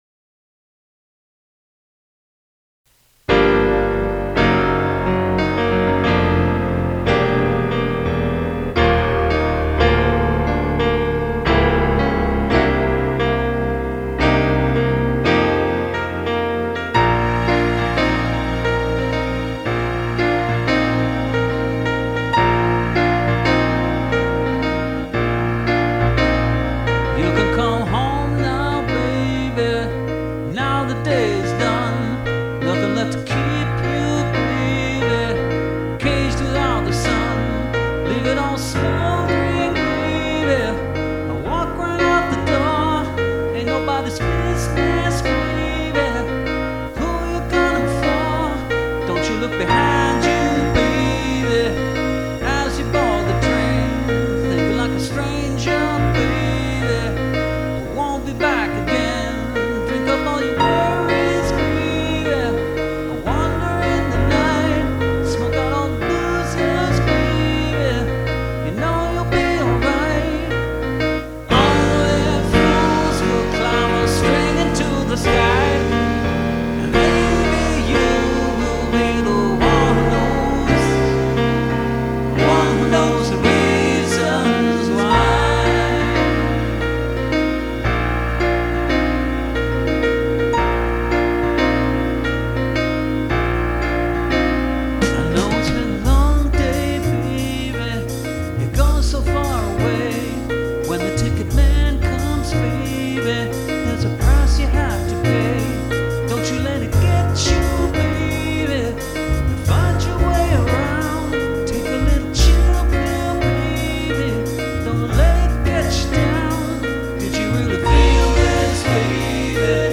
It still has many problems; I'm working with all-digital technology, except for the microphone and I don't have the violin solo I want
Well, it's definitely not hip-hop.
Everything written and played by me